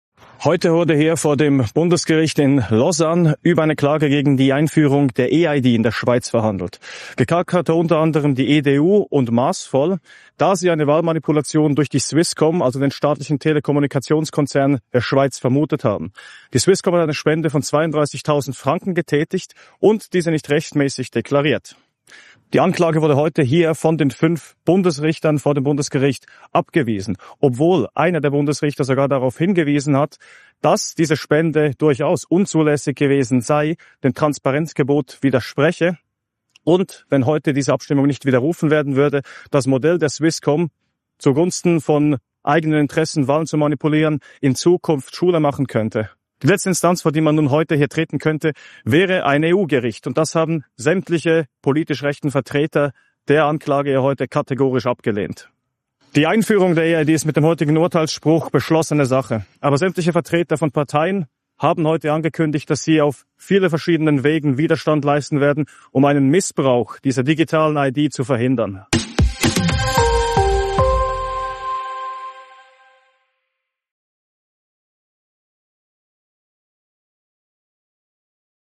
fasst die Lage direkt nach dem Urteil vor Ort